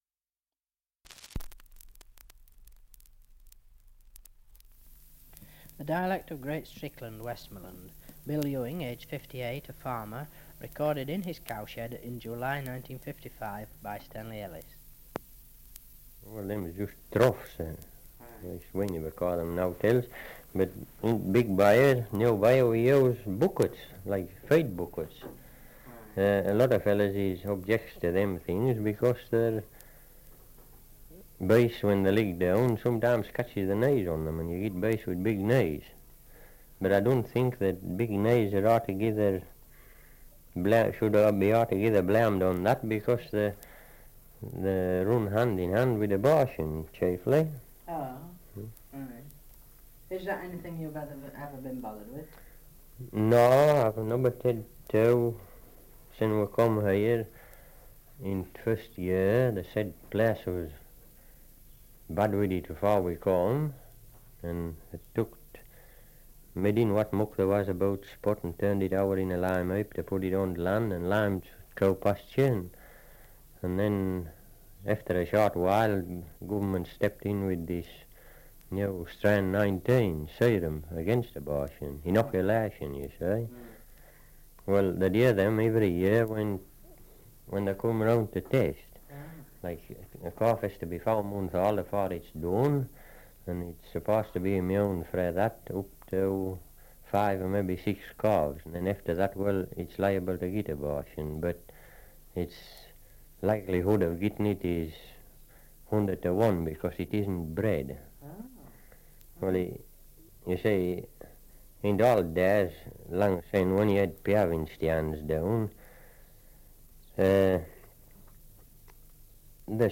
1 - Survey of English Dialects recording in Great Strickland, Westmorland
78 r.p.m., cellulose nitrate on aluminium